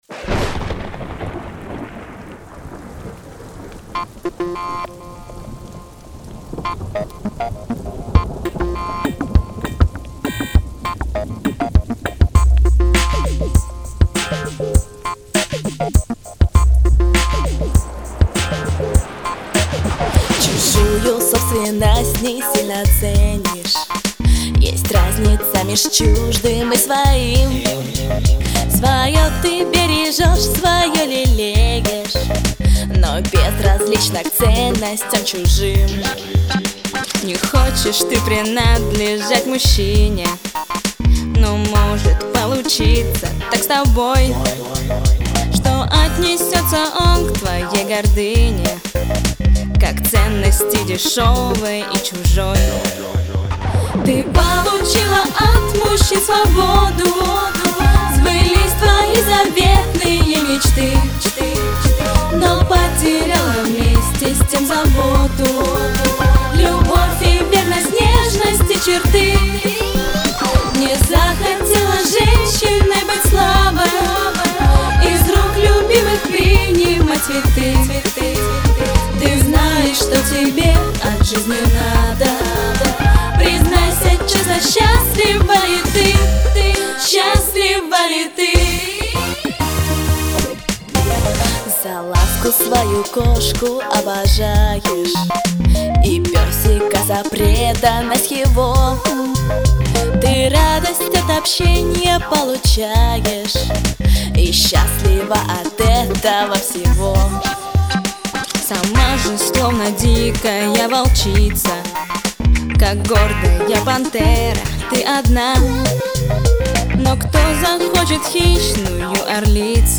Очень странная песня для поп-группы!
Pop-jazz